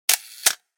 جلوه های صوتی
دانلود صدای دوربین 6 از ساعد نیوز با لینک مستقیم و کیفیت بالا